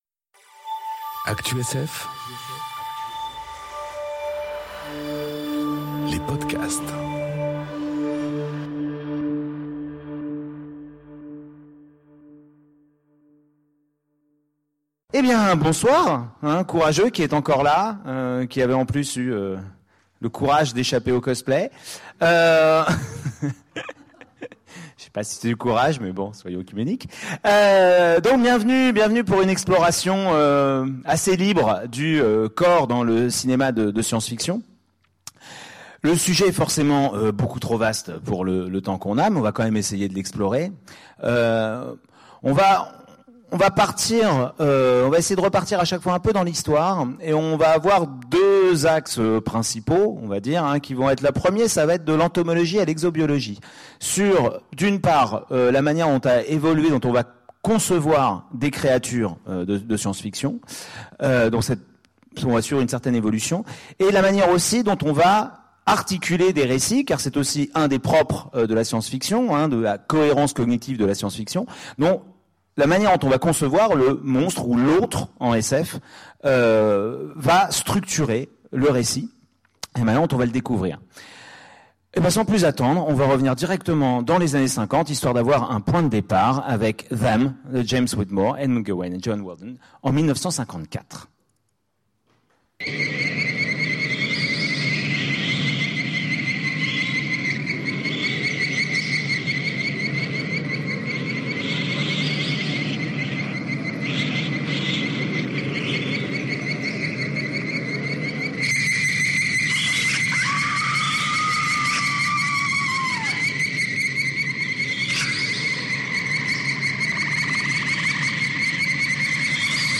Conférence Corps et cinéma enregistrée aux Utopiales 2018